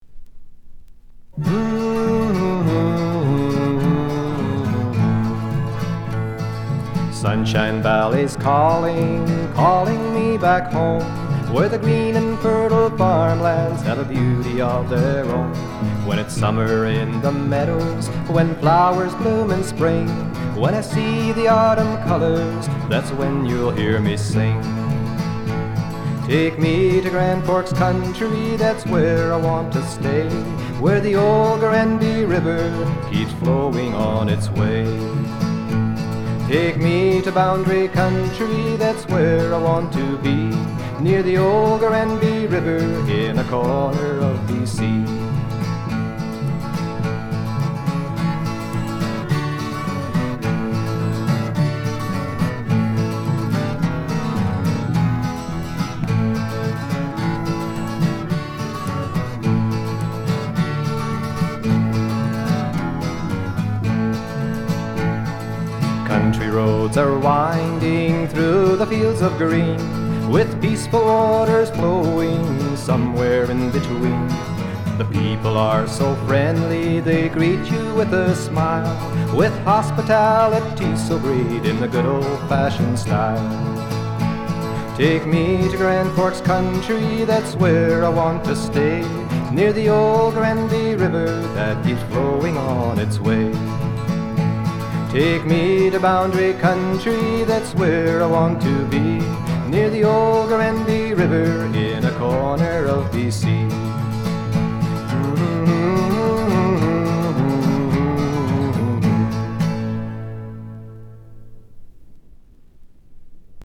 Doukhobor singer-songwriter